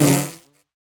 minecraft / sounds / mob / bee / hurt1.ogg
hurt1.ogg